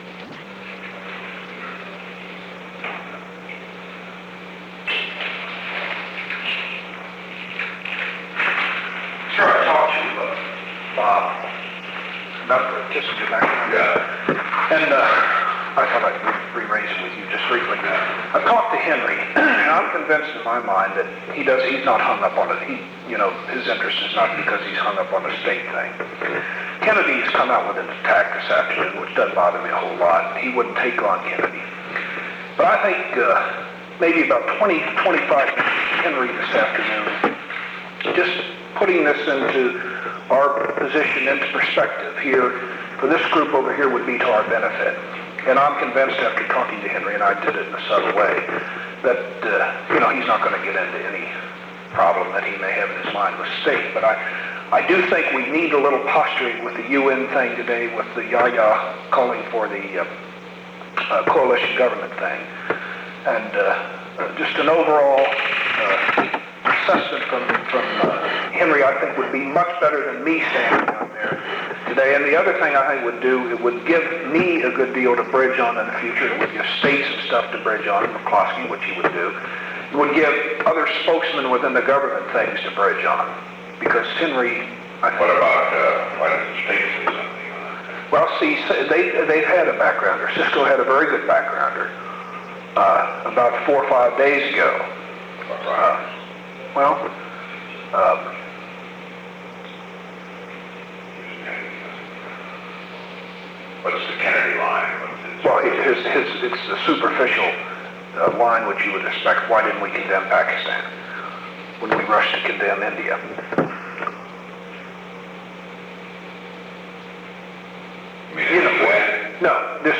On December 7, 1971, President Richard M. Nixon and Ronald L. Ziegler met in the Oval Office of the White House from 2:59 pm to 3:02 pm. The Oval Office taping system captured this recording, which is known as Conversation 631-002 of the White House Tapes.